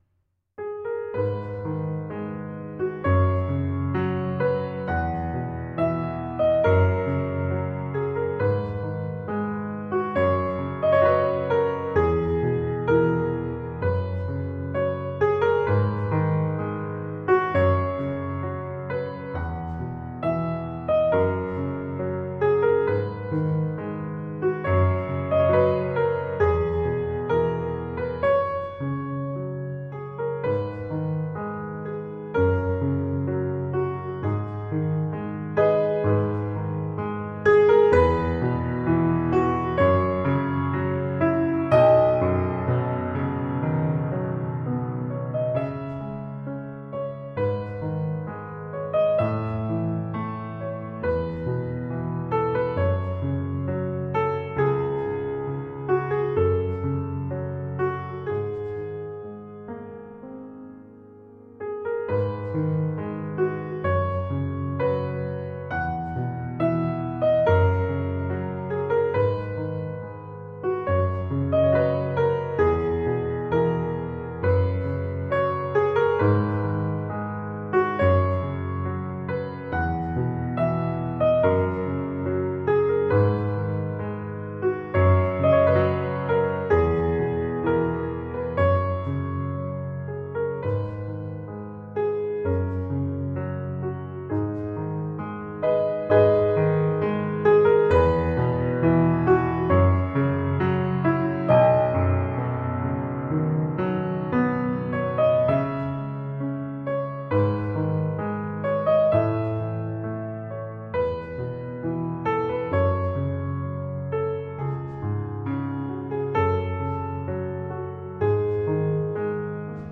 Emotional piano explorations.